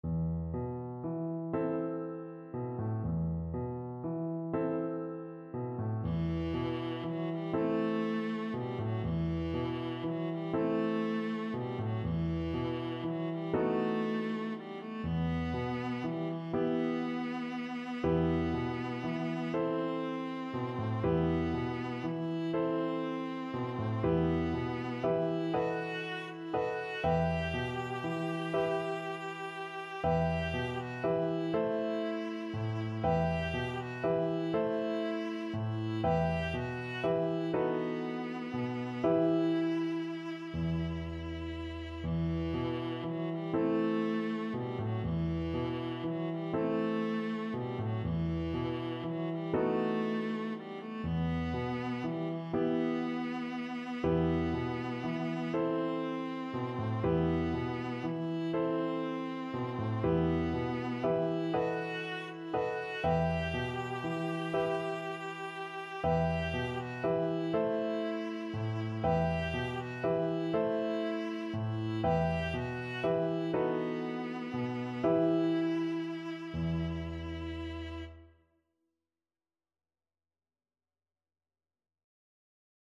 Viola
E minor (Sounding Pitch) (View more E minor Music for Viola )
6/8 (View more 6/8 Music)
Gently rocking .=c.40
Traditional (View more Traditional Viola Music)